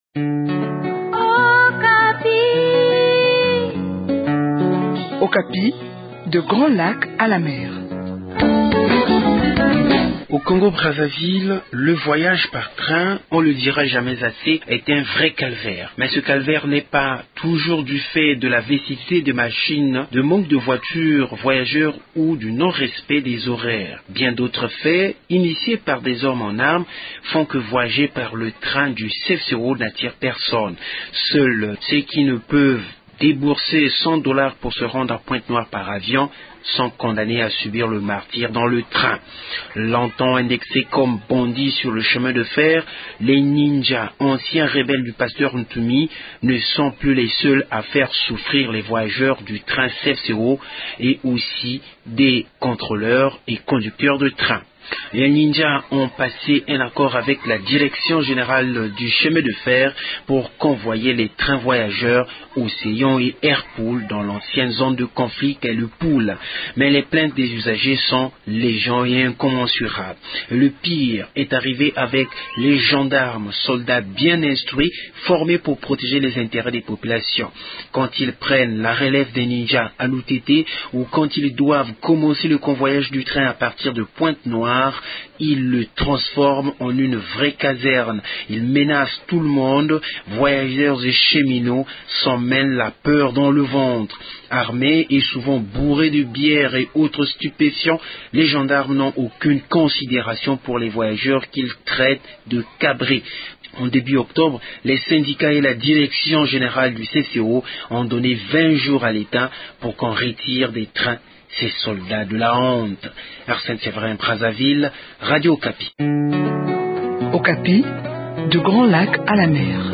depuis Brazzaville